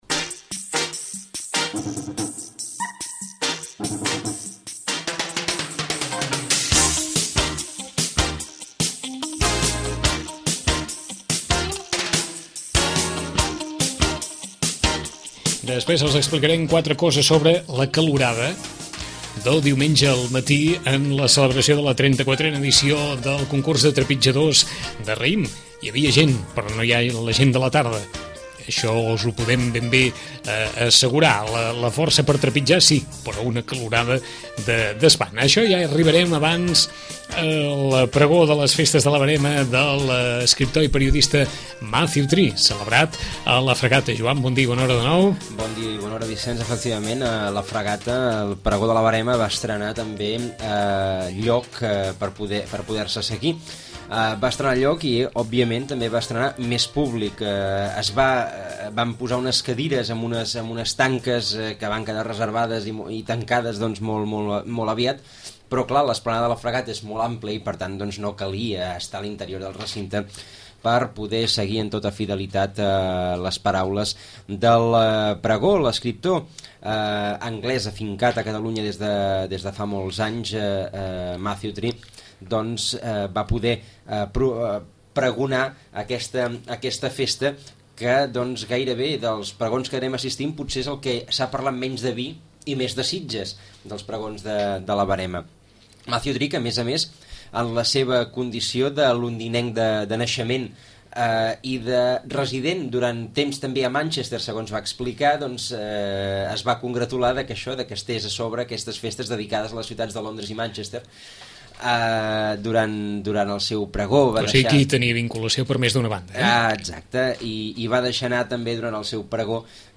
el pregó de la festa de la verema d’anguany